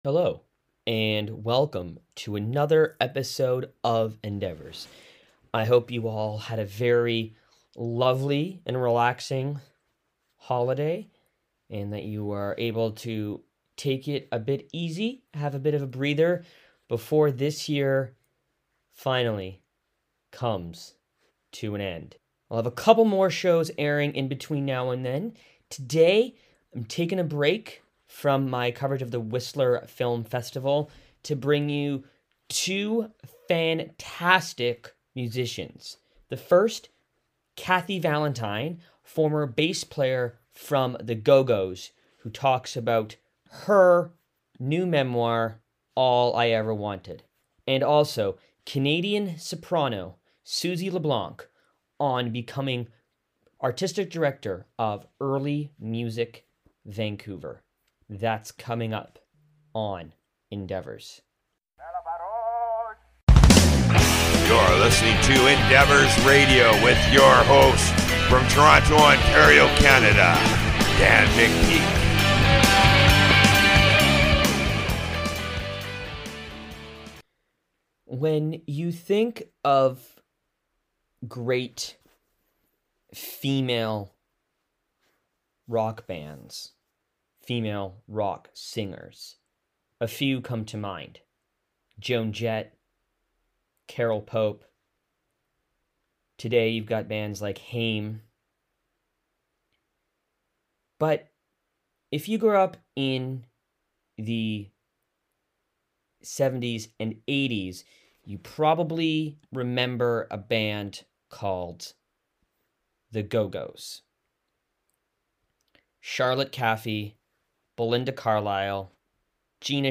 Former Go-Go's Bassist Kathy Valentine; Soprano Suzie LeBlanc